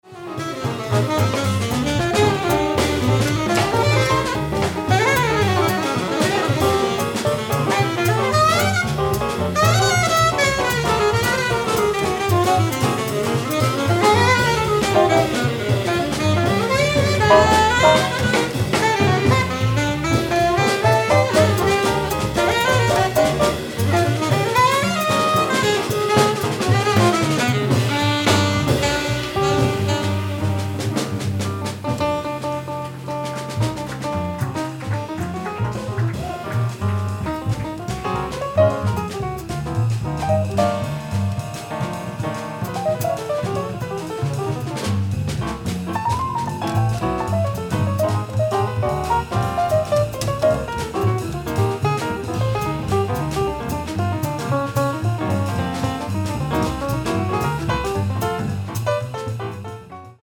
piano
acoustic bass
drums
modern mainstream jazz